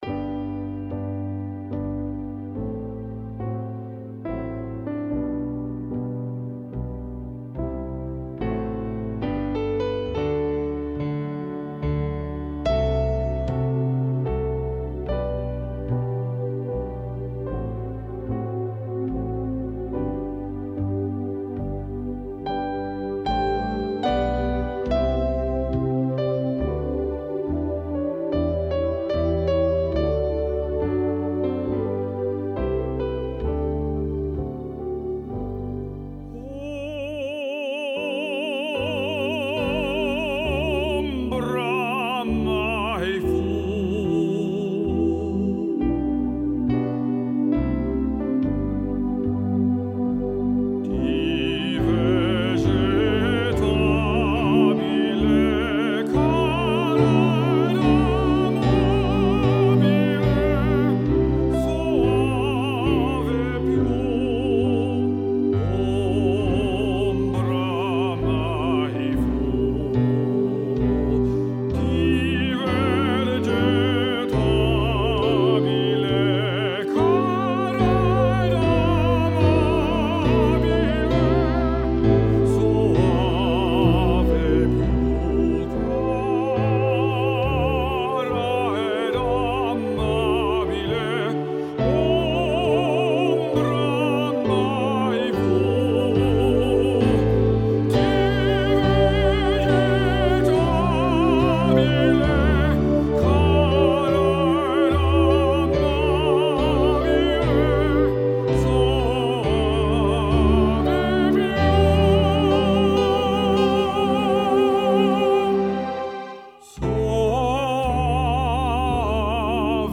powerful tenor voice